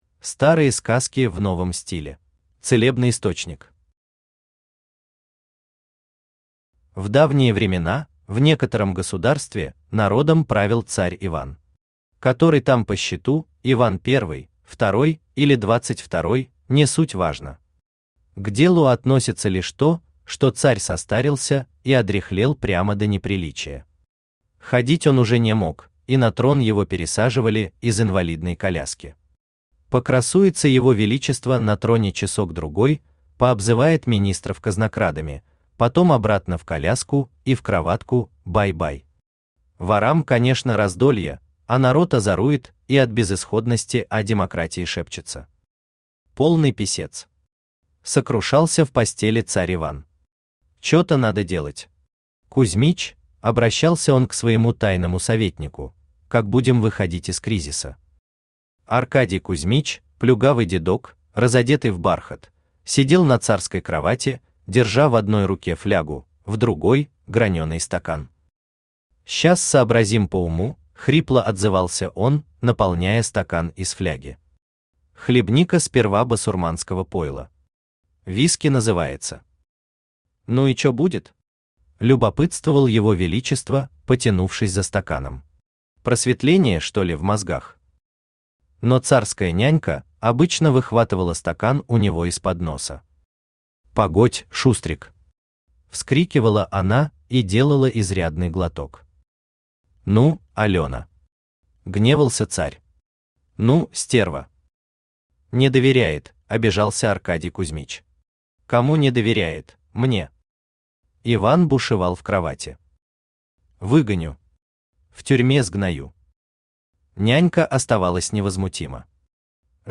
Aудиокнига Никаких вам золушек Автор Валерий Вайнин Читает аудиокнигу Авточтец ЛитРес.